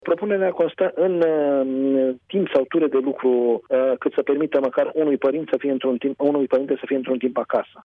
Guvernul a găsit o soluție pe care ar urma s-o aplice de săptămâna viitoare, a spus pentru Europa FM – Nelu Tătaru, secretar de stat în ministerul Sănătății: